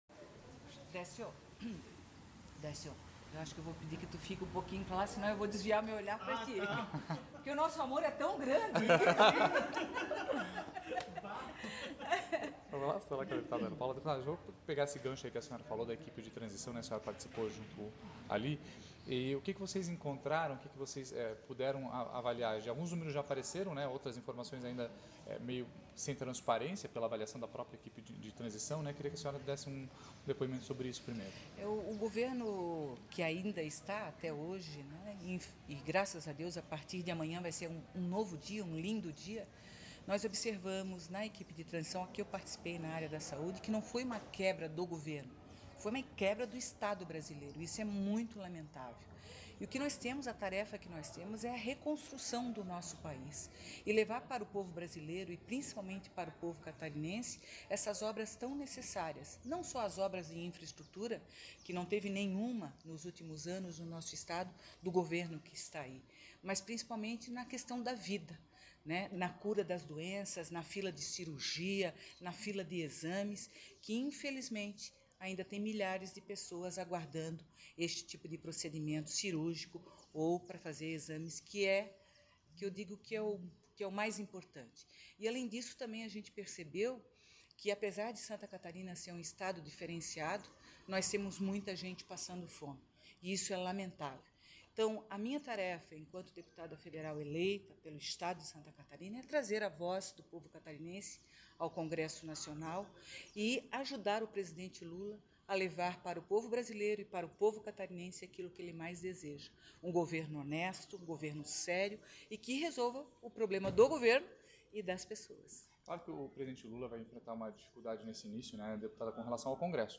ENVIADO ESPECIAL DO ND Brasília
Ouça, a seguir, a fala da deputada federal: